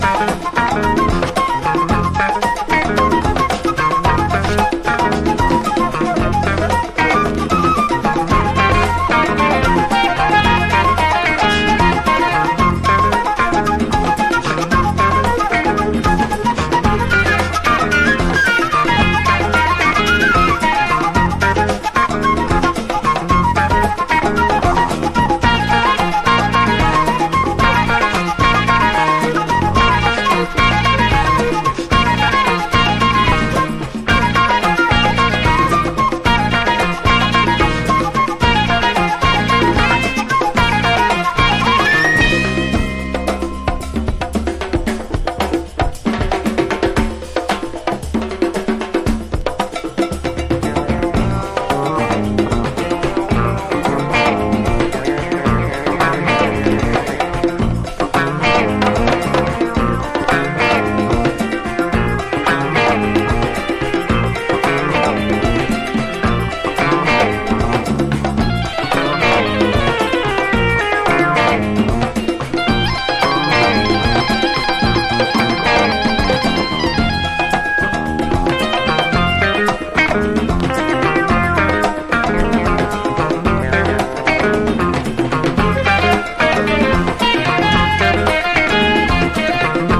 ナイスカバーも多数収めたディスコ好盤！
タメの効いたグルーヴに軽快なフルートが絡むラテンA2やコズミック・ラテンディスコのA4も◎！